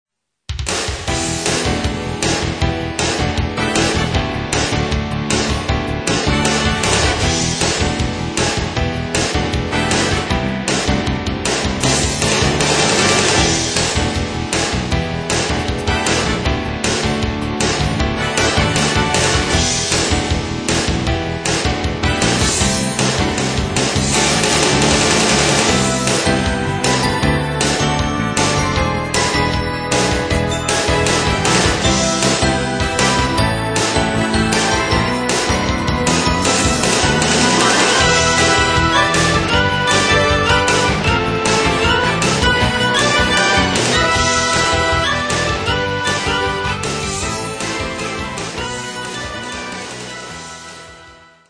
ジャンルとしては、ポップス、フュージョン、リスニング系、クラシック風まで多彩です。